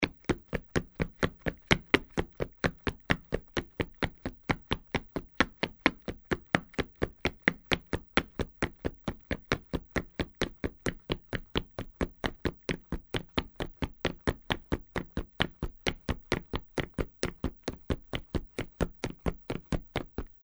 在较硬的路面上奔跑2－YS070525.mp3
通用动作/01人物/01移动状态/01硬地面/在较硬的路面上奔跑2－YS070525.mp3
• 声道 立體聲 (2ch)